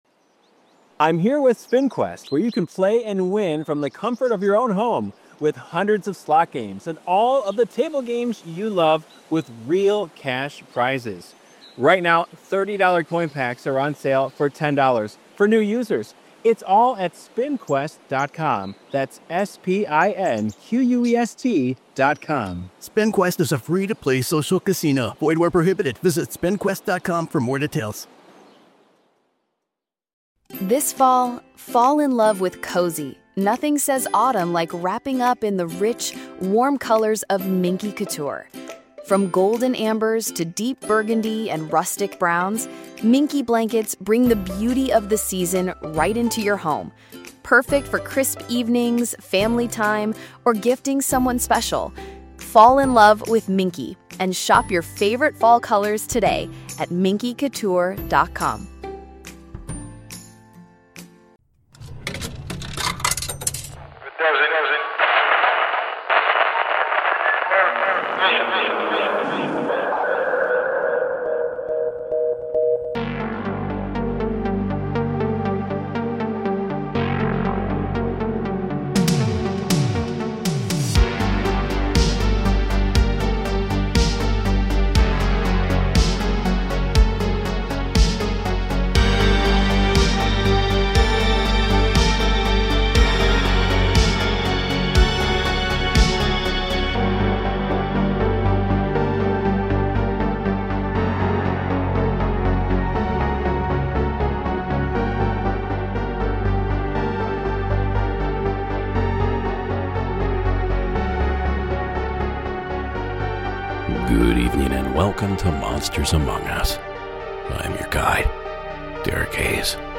We have some super strange calls for you tonight, horse-headed entities, UFOs, and the notorious mirrored men return to the show.